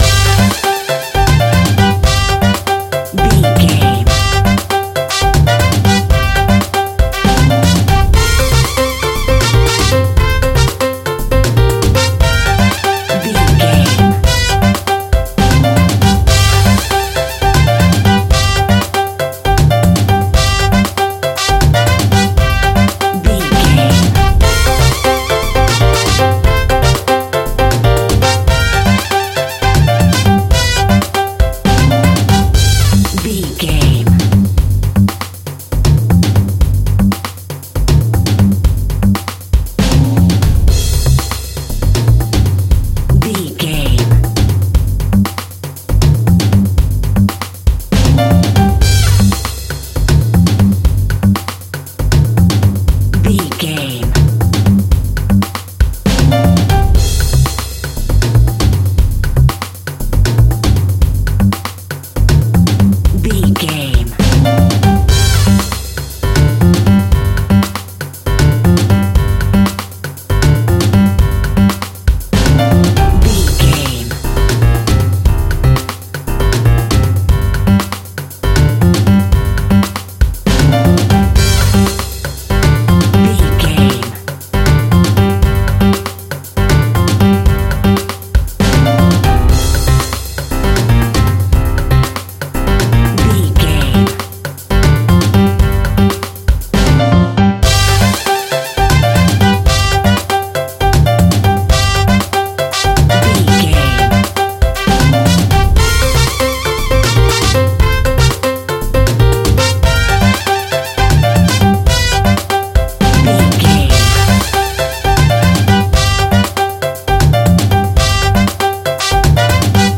Ionian/Major
Fast
percussion
brass
bass guitar
piano